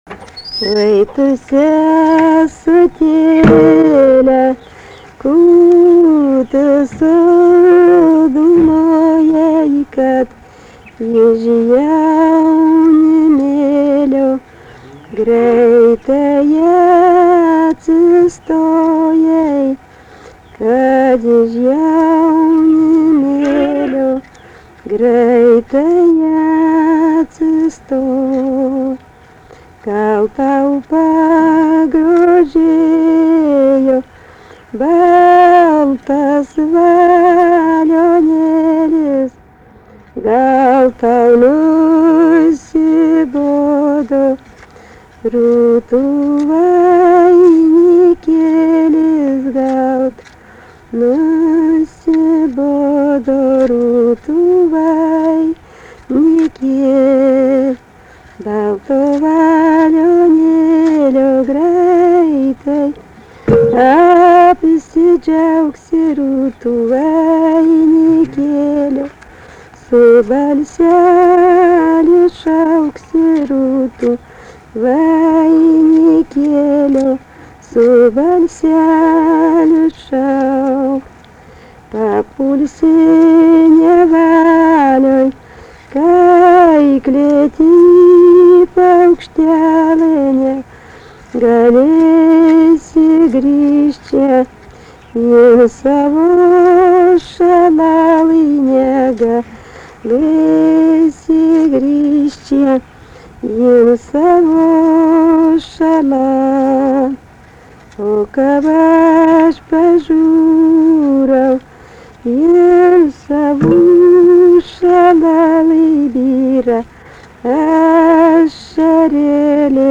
daina, vestuvių
Atlikimo pubūdis vokalinis
Pastabos Pabaigoj burzgimas